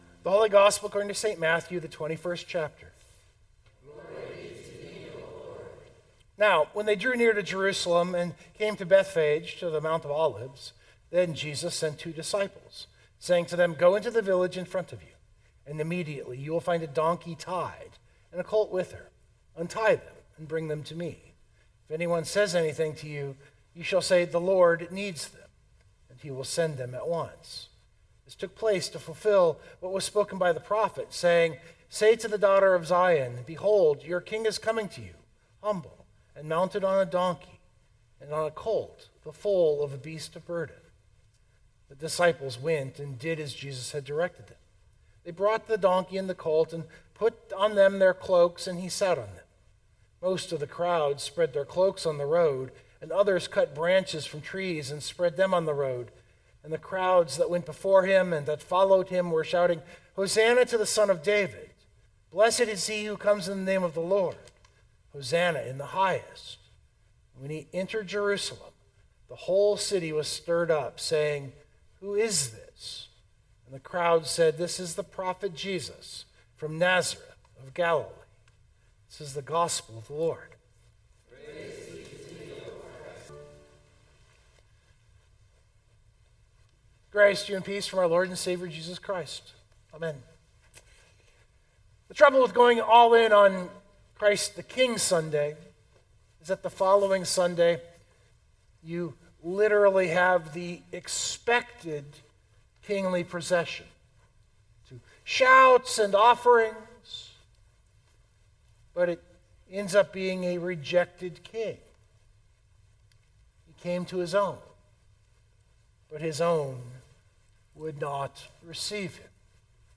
113025 sermon Download Biblical Text: Matthew 21:1-11 First Sunday of Advent.